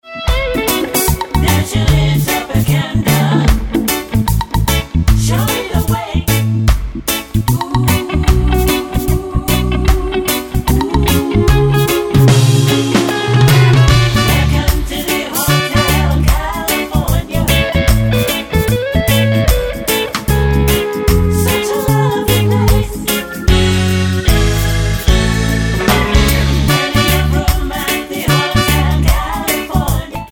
Tonart:F#m mit Chor